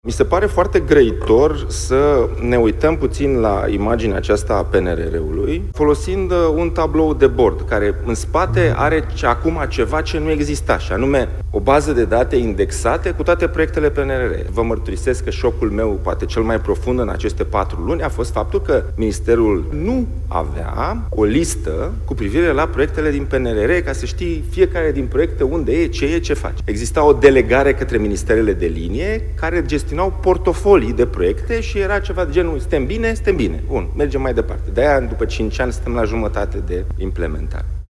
Acum, fiecare proiect poate fi urmărit public, județ cu județ, explică ministrul Investițiilor și Proiectelor Europene, Dragoș Pîslaru, în cadrul unei conferințe organizate de platforma „Curs de Guvernare”.